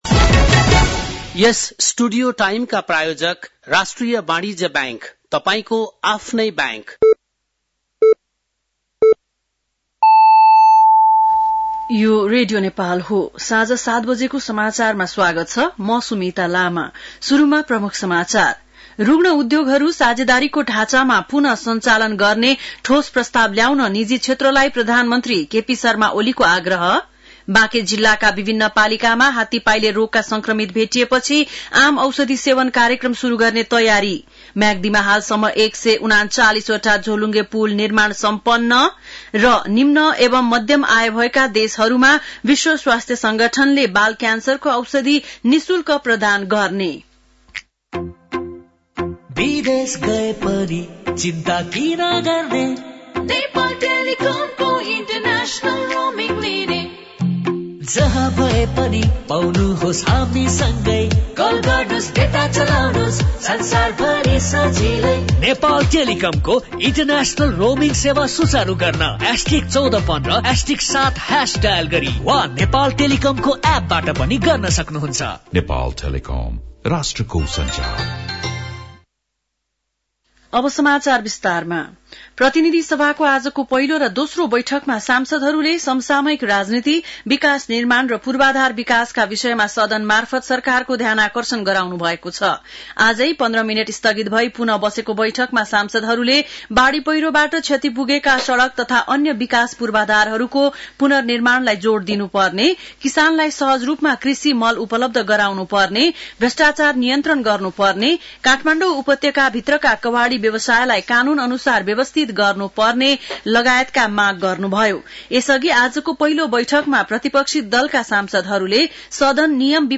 बेलुकी ७ बजेको नेपाली समाचार : ३० माघ , २०८१